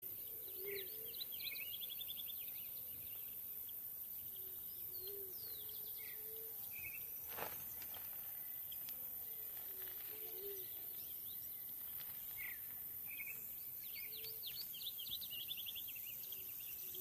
Arañero Cara Negra (Geothlypis velata)
Nombre en inglés: Southern Yellowthroat
Fase de la vida: Adulto
Condición: Silvestre
Certeza: Vocalización Grabada